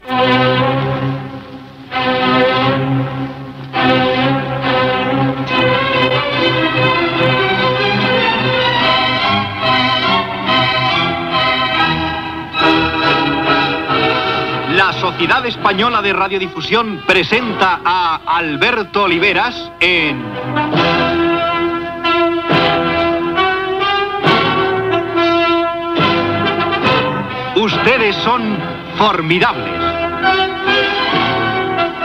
Sintonia d'entrada del programa .